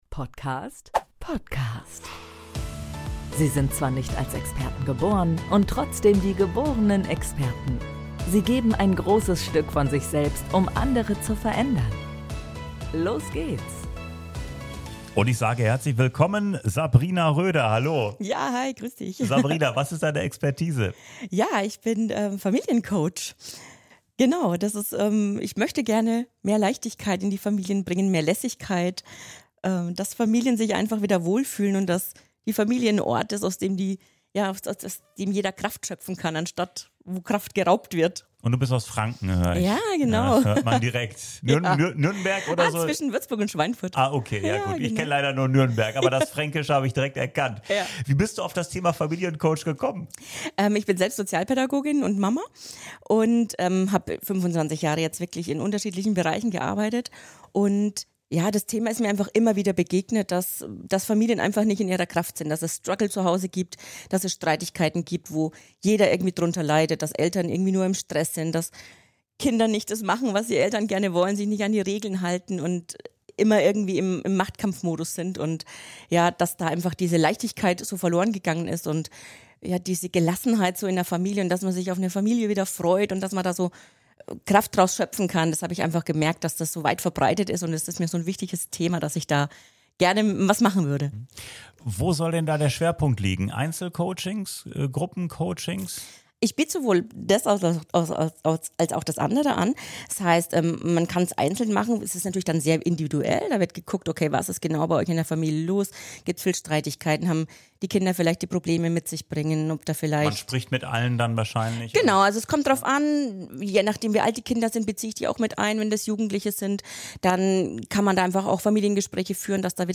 Zu Gast beim Experten-Postcast